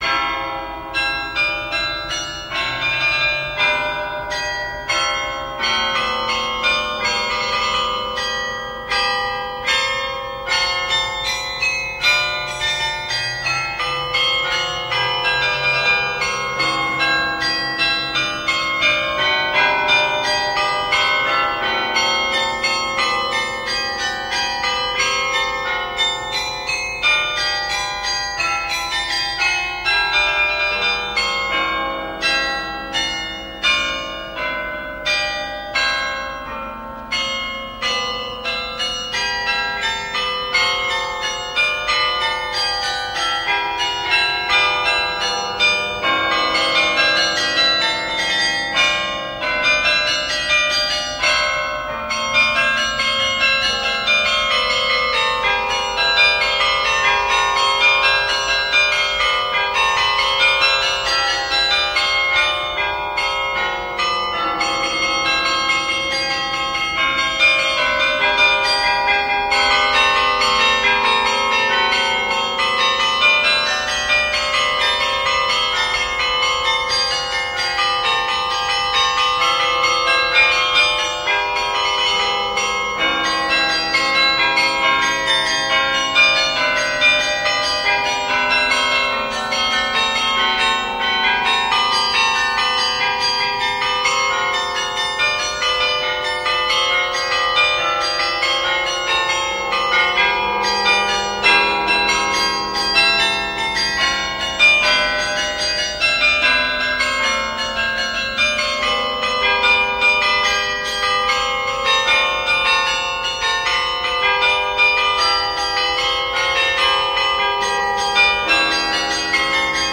The Carillon